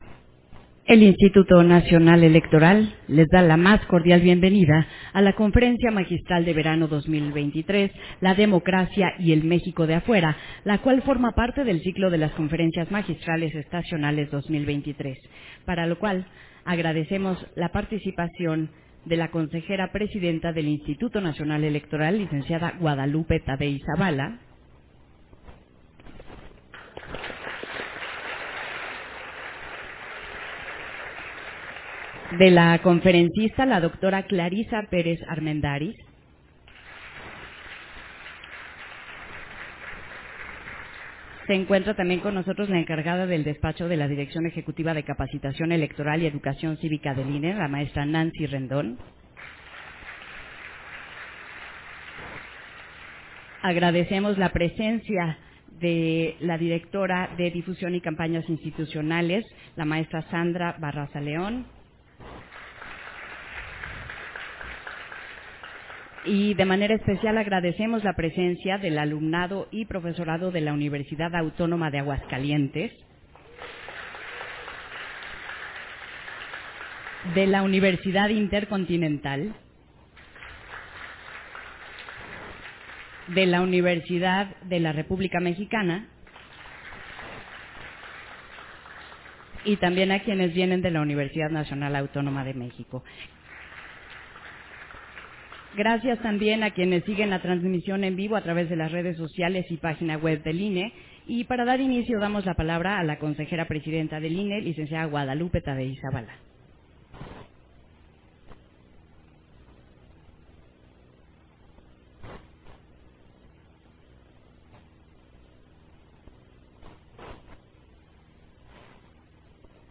051023_AUDIO_CONFERENCIA-MAGISTRAL-LA-DEMOCRACIA-Y-EL-MÉXICO-DE-AFUERA - Central Electoral